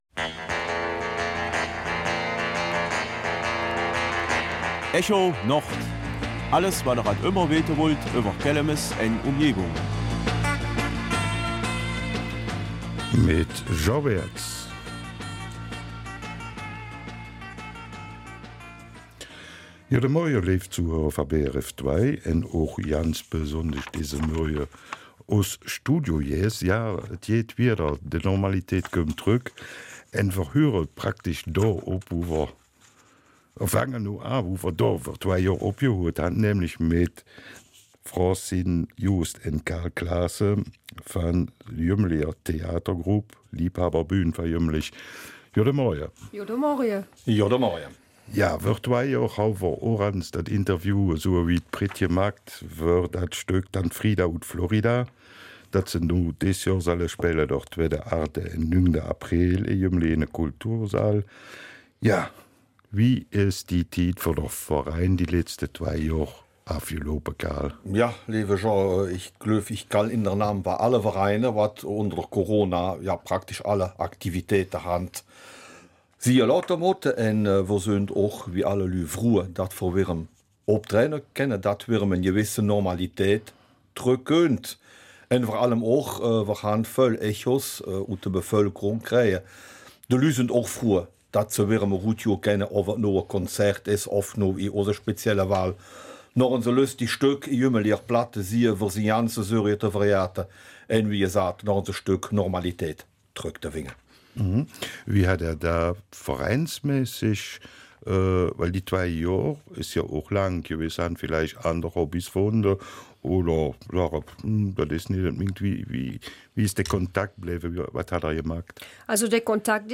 Kelmiser Mundart: ''Tante Frida aus Florida'' auf Gemmenicher Platt
Unsere Studiogäste verraten uns, welche Ziele der Verein verfolgt, was es mit der Kindergruppe auf sich hat und der damit verbundenen Pflege vom Plattdeutschen, wie die Kulissen entstehen und geändert werden.